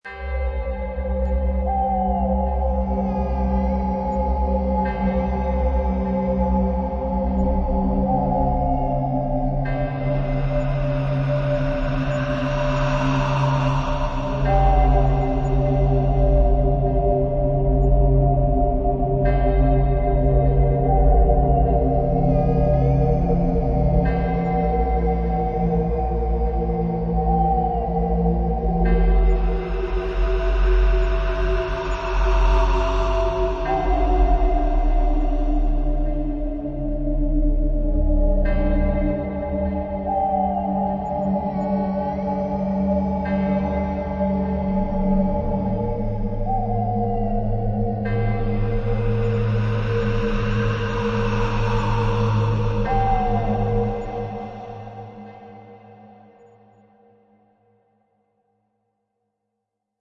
Download Scary Halloweens sound effect for free.
Scary Halloweens